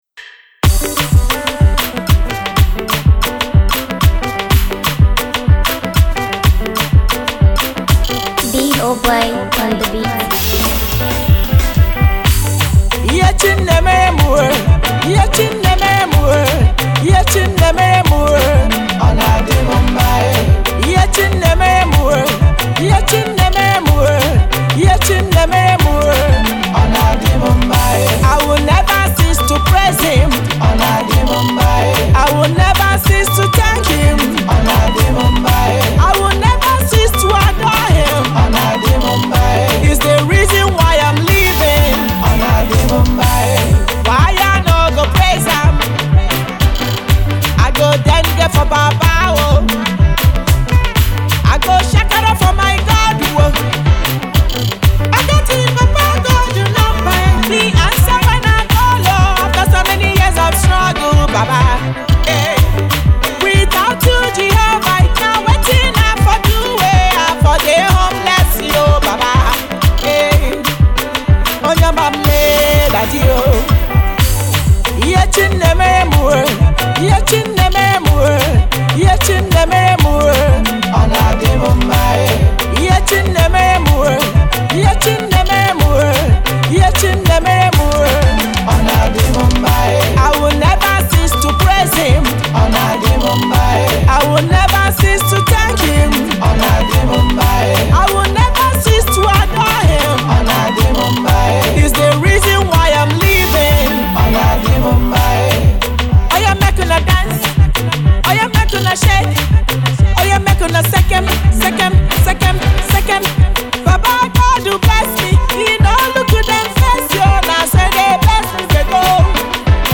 African Contemporary/Indigenous Gospel
praise song